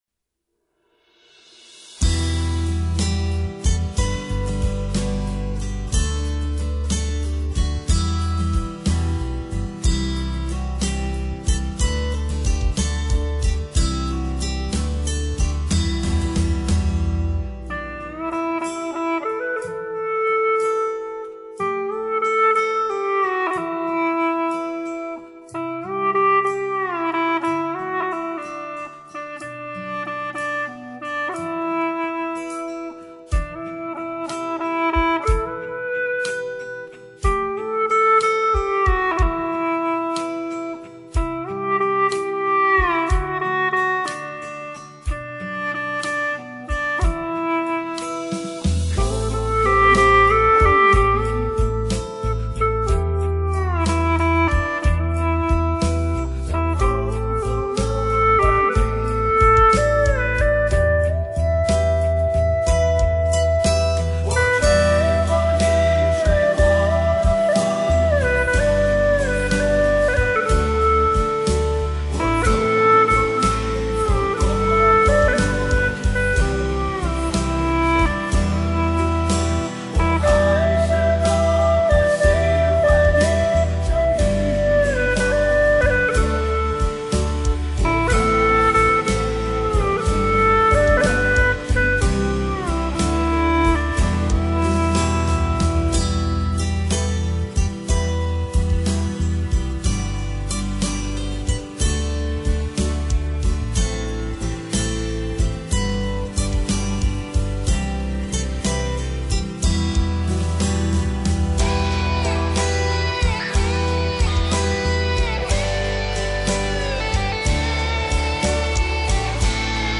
调式 : G